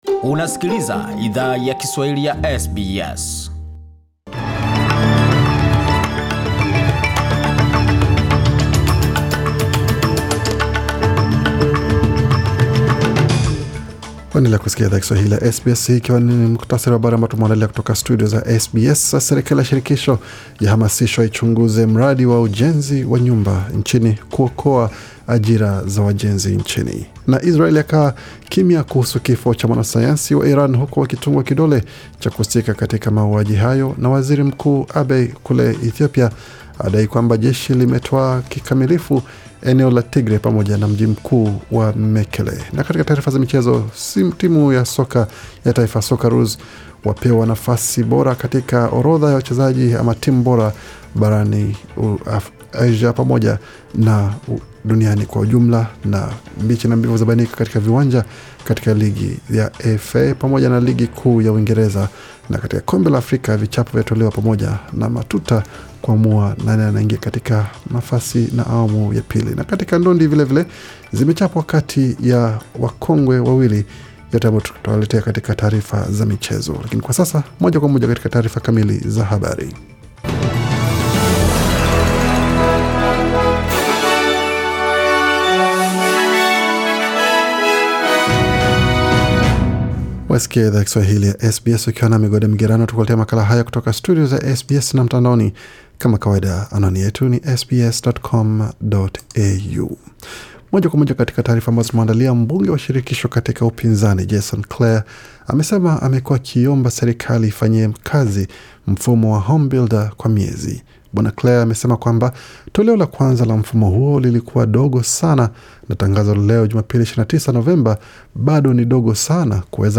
Taarifa ya habari 29 Novemba 2020